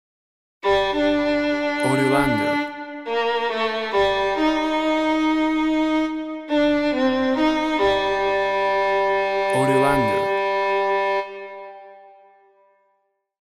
Musical piece induction of fear and terror with solo violin.
Tempo (BPM) 70